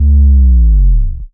G#_07_Sub_01_SP.wav